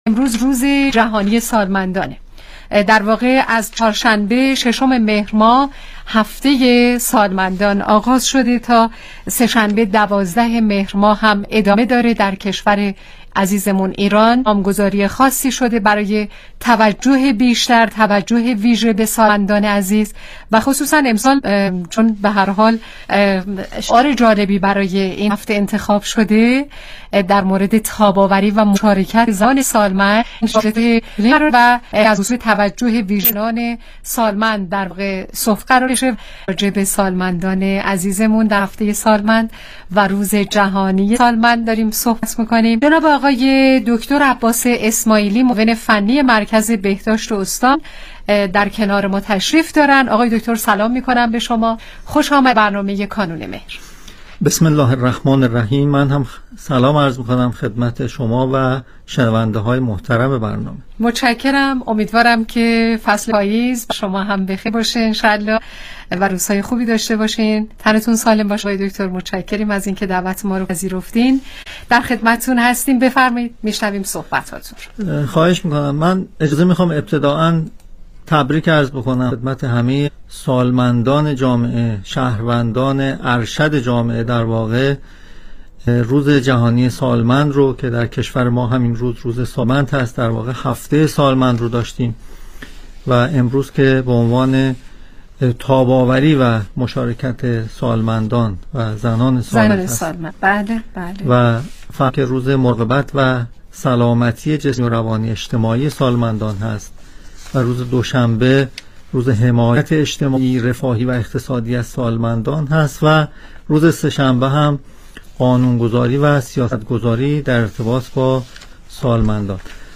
برنامه رادیویی کانون مهر بمناسبت روز جهانی سالمند، ۹ مهر ماه